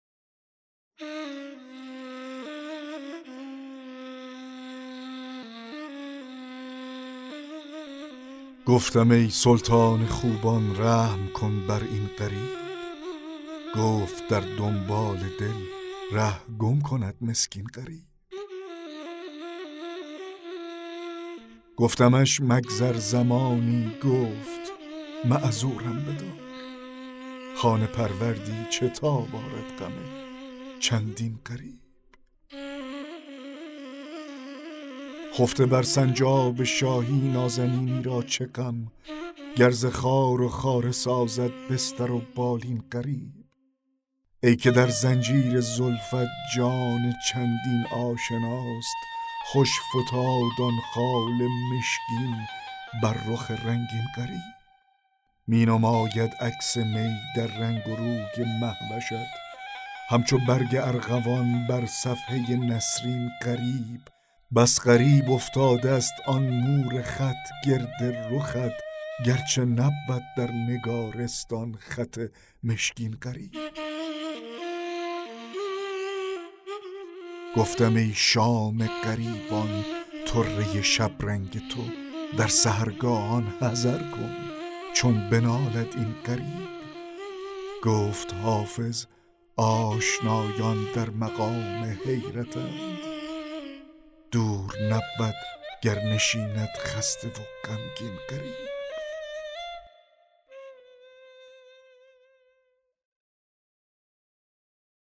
دکلمه غزل 14 حافظ
دکلمه-غزل-14-حافظ-گفتم-ای-سلطان-خوبان-رحم-کن-بر-این-غریب.mp3